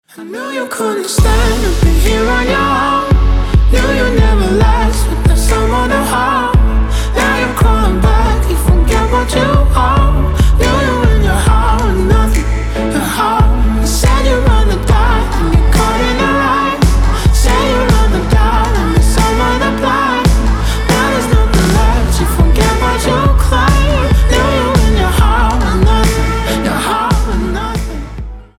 • Качество: 256, Stereo
поп
мужской вокал
Melodic
romantic
vocal